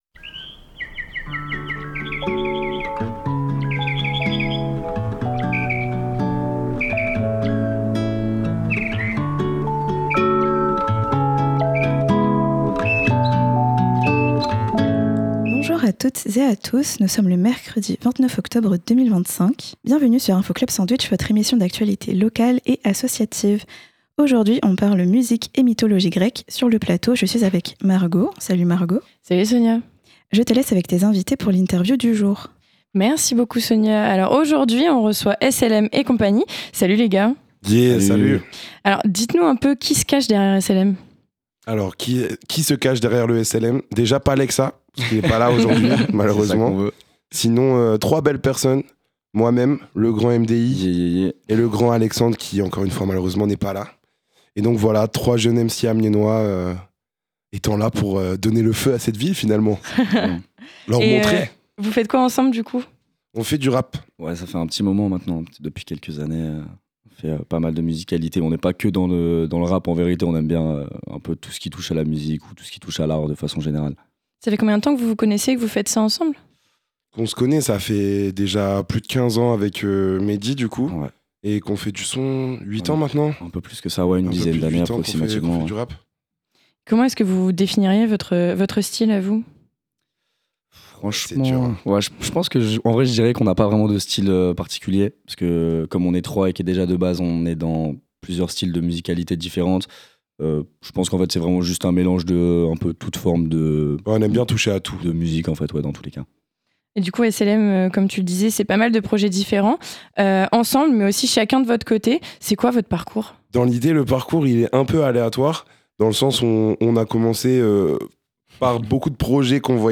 Aujourd'hui nous avons reçu SLM, trio de rappeurs amiénois. C'est eux qui sont à l'initiative de la soirée battle de rap de ce jeudi 30.